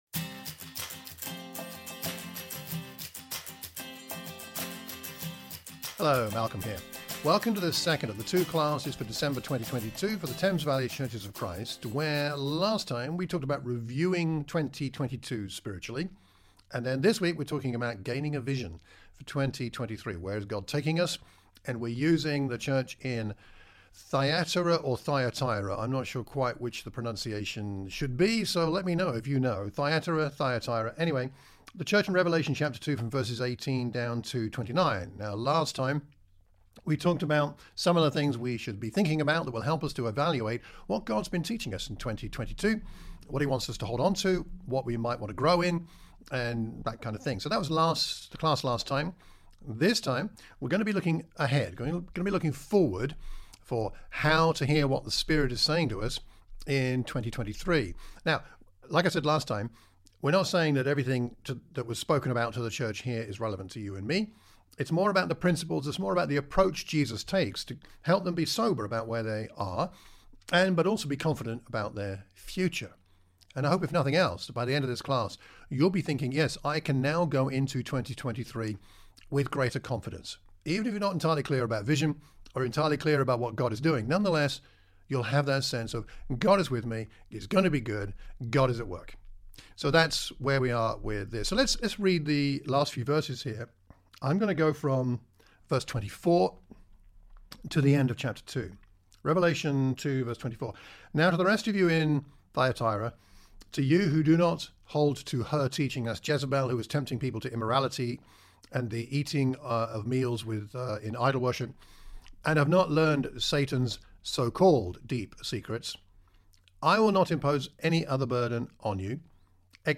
Lesson 2 - Vision for 2023